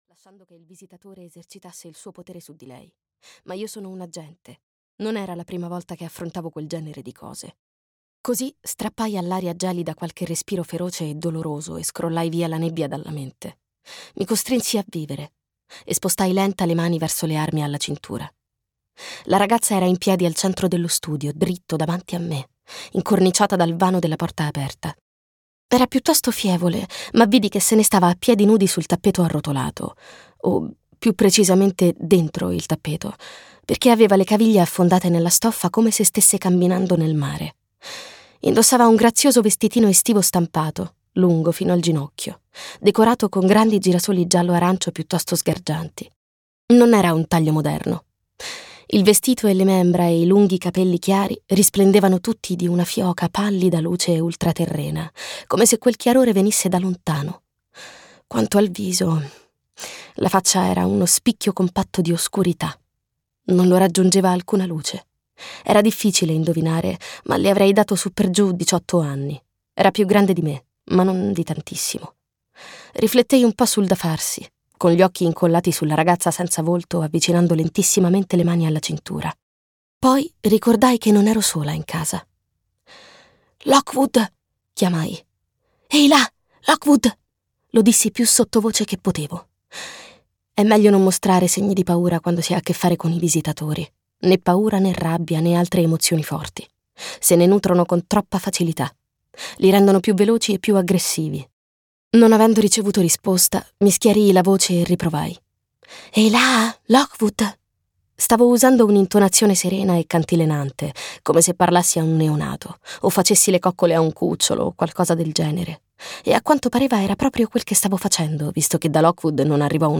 "Lockwood & Co. La scala urlante" di Jonathan Stroud - Audiolibro digitale - AUDIOLIBRI LIQUIDI - Il Libraio